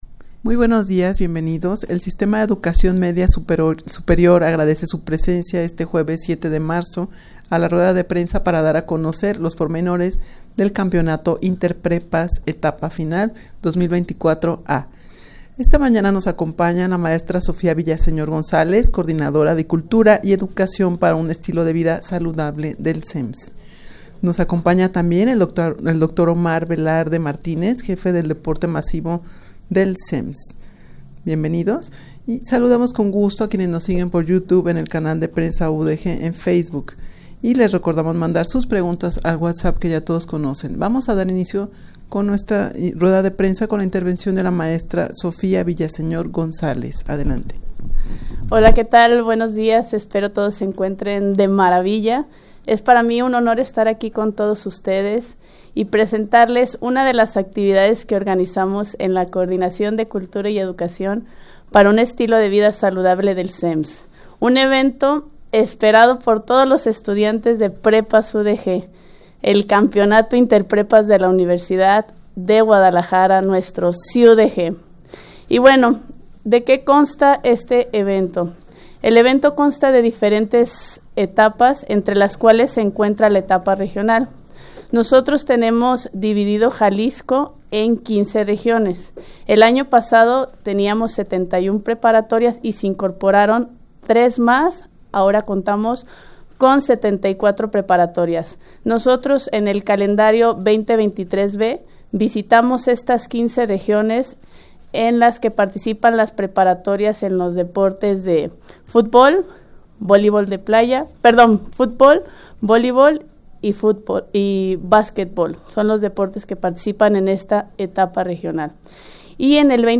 Audio de la Rueda de Prensa
rueda-de-prensa-para-dar-a-conocer-los-pormenores-del-campeonato-interprepas-etapa-final-2024-a.mp3